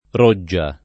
roggia
roggia [ r 1JJ a ] s. f. («canale»); pl. ‑ge